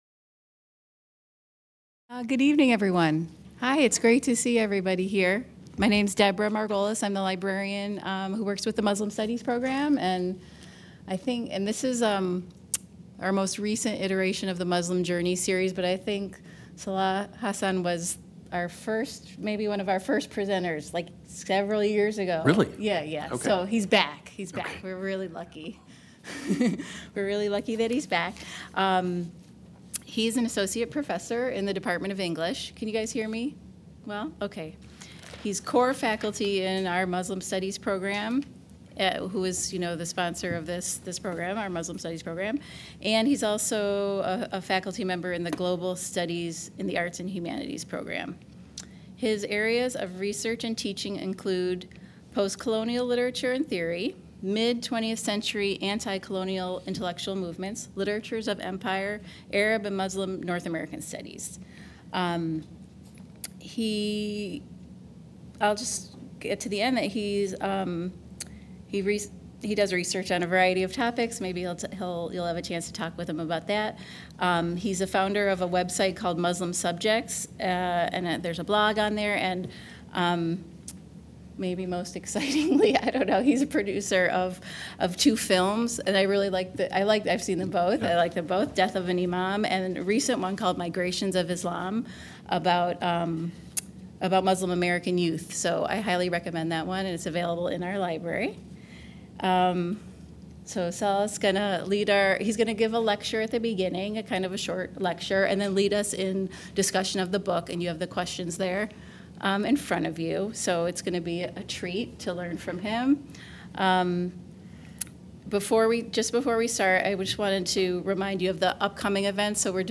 Muslim Journeys Book Club event